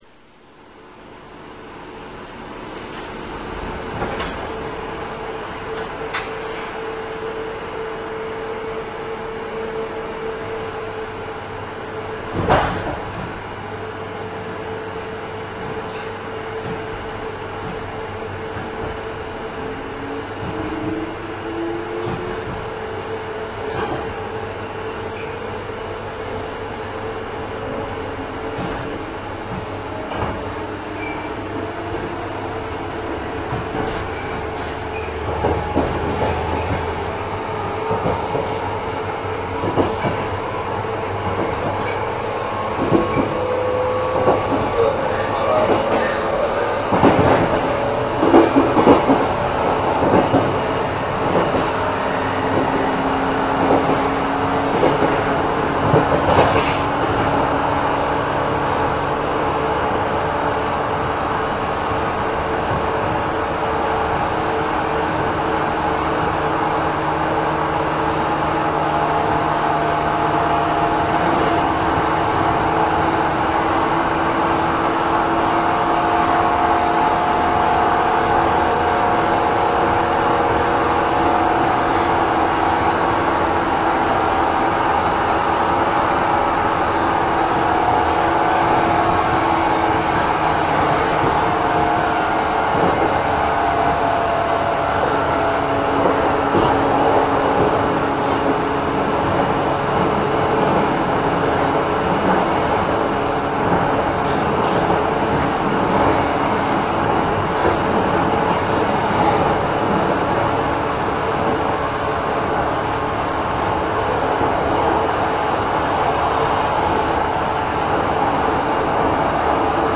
走行音[455a.ra/499KB]
制御方式：抵抗制御
主電動機：120kW×4(MT54型、歯車比4.21)